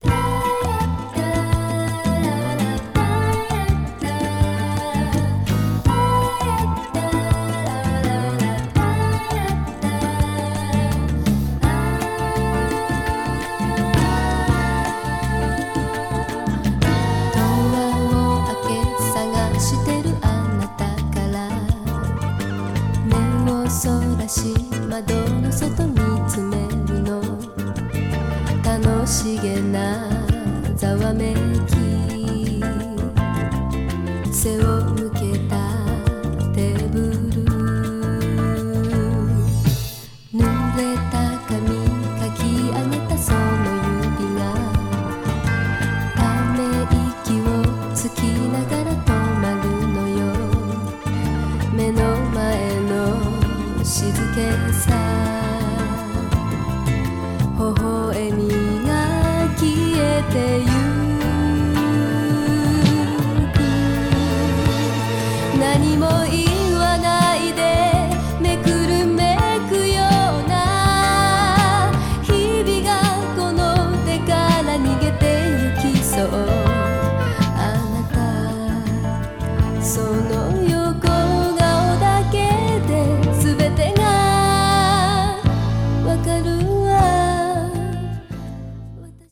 and while the musical direction leans toward folk-style pop
guitar
drums
bass
percussion
clear singing voice
city pop   japanese pop   light mellow